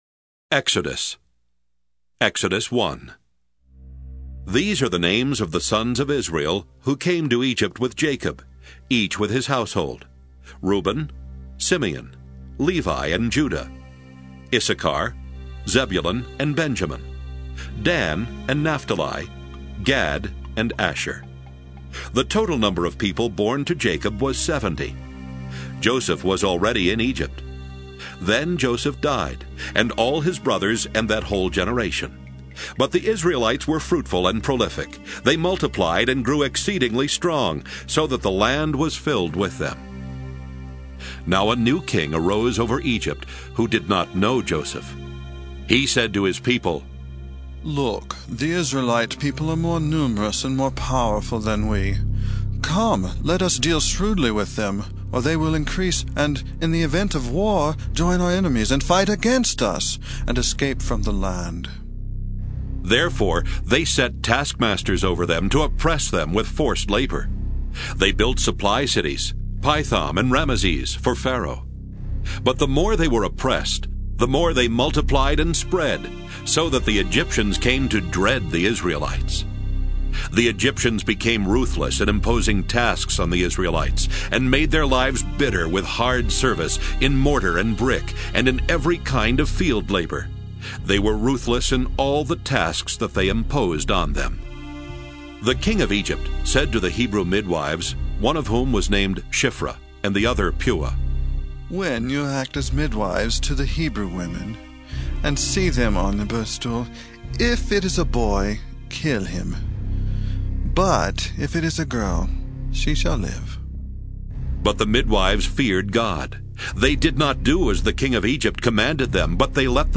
NRSV MP3 Bible, New Revised Standard Version Drama
Audio Bible Download